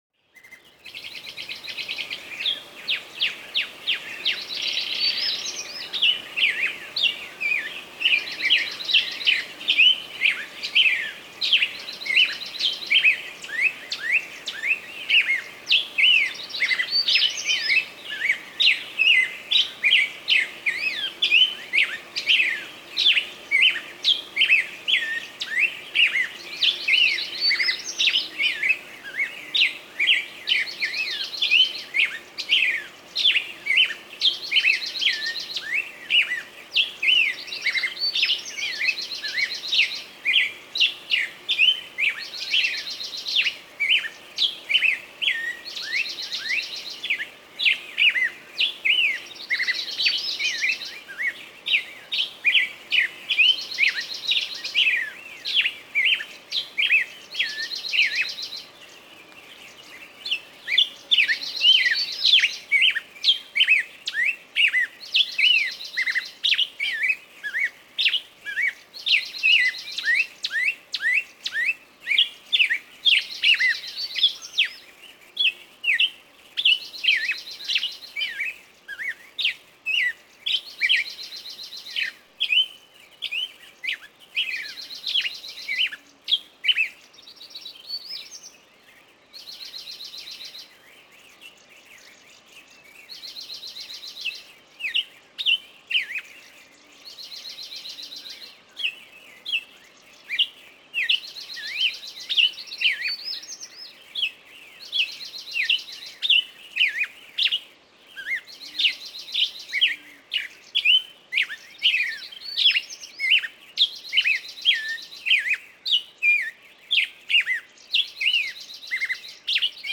сопровождение "Голоса птиц в живой природе" и практически, сразу же попадаете в атмосферу реальной выставки. 2016 - 2017 г.г.
solovi_i_rajskie_pticy_-_penie_ptic.mp3